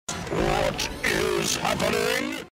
Play, download and share Dalek original sound button!!!!